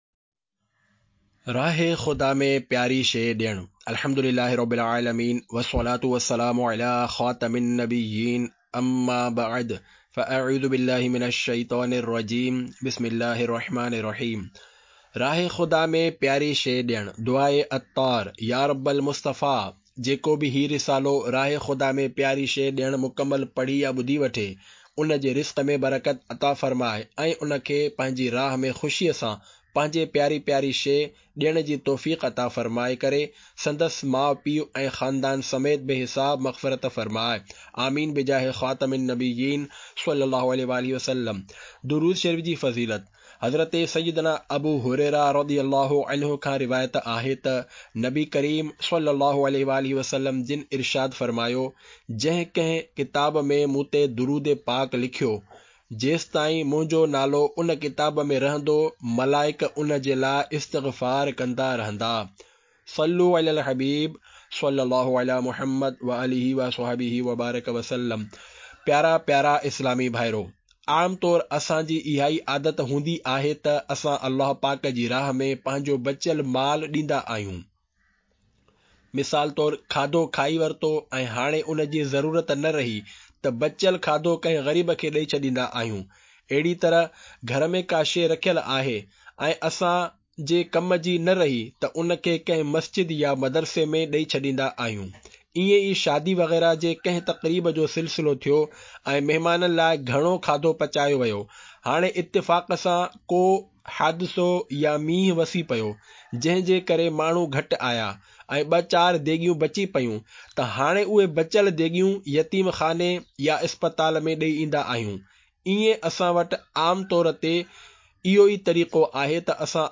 Audiobook - Rah e Khuda Me Pyari Cheez Dena (Sindhi)